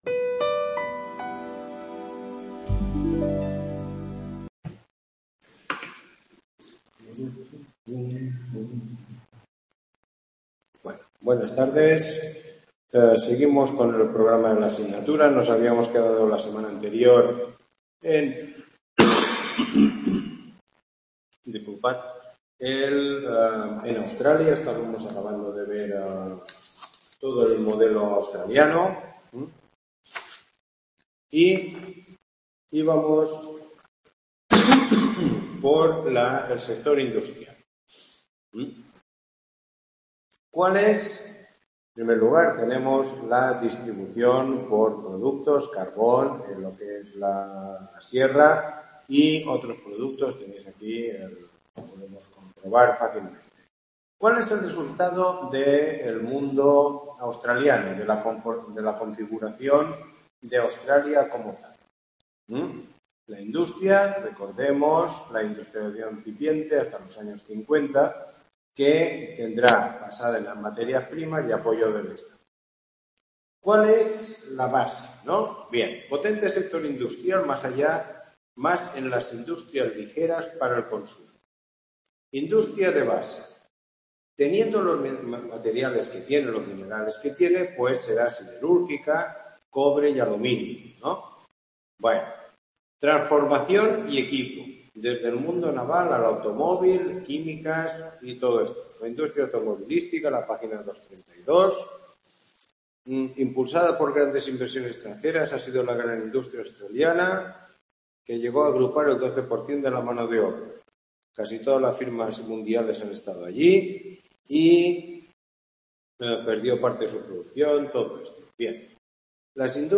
Tutoría 9